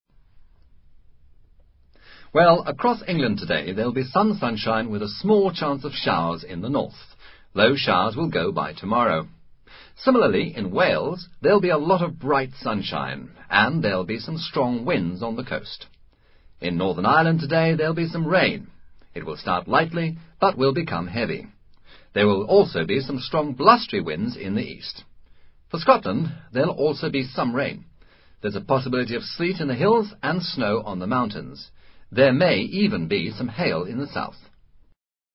Archivo de audio que reproduce un pronóstico del tiempo.